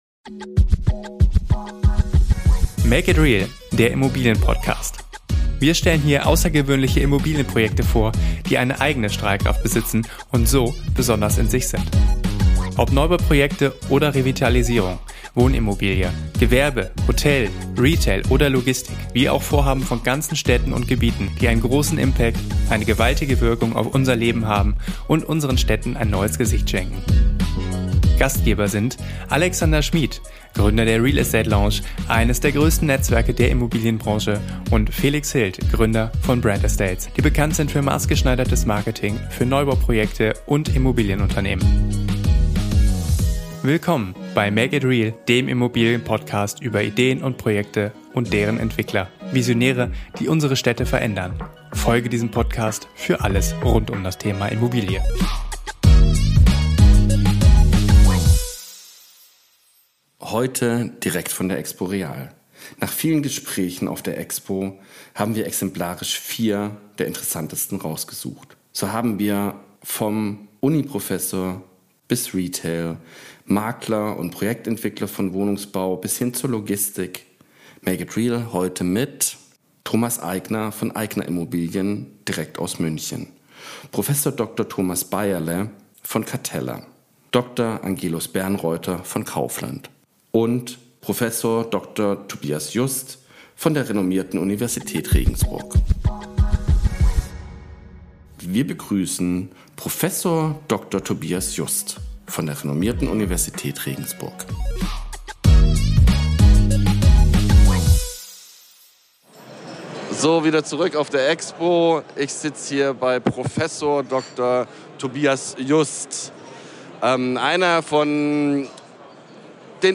In dieser Podcast-Folge berichten wir wieder einmal direkt aus München von der EXPO REAL mit sehr interessanten Gesprächspartnern. Aus allen Gesprächen haben wir vier ausgewählt.